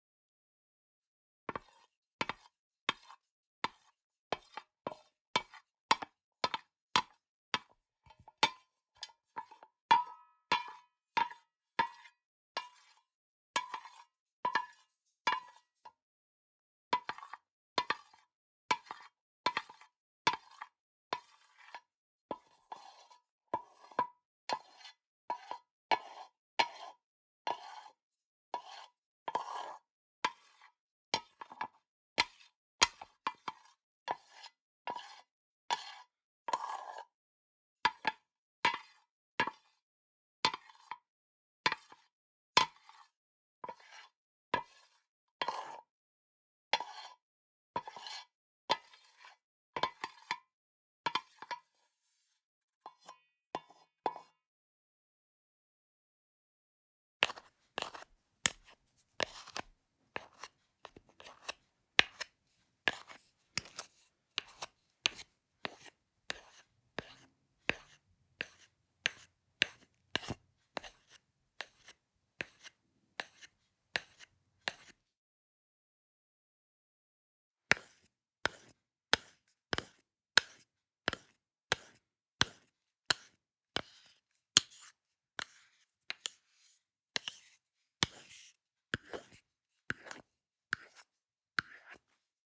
Шуршание деревянной ложки при перемешивании еды в кулинарной посуде